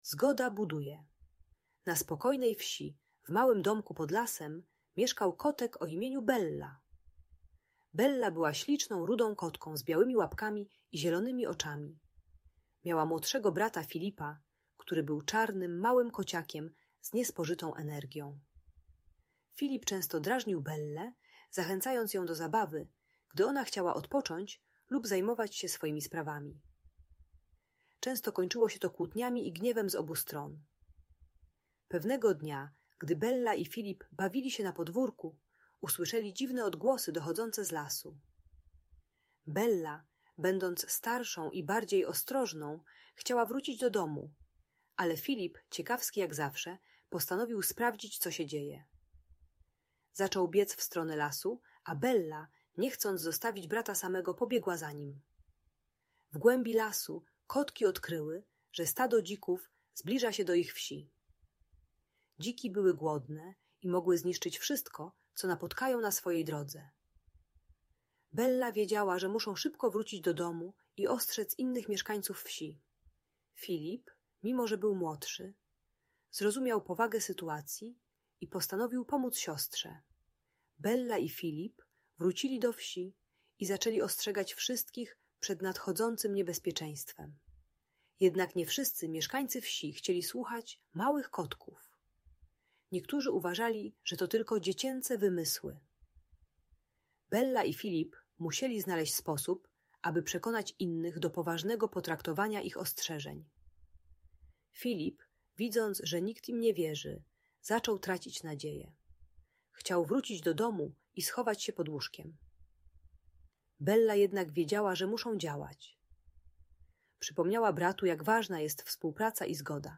Zgoda Buduje: Story o Belli i Filipie - Rodzeństwo | Audiobajka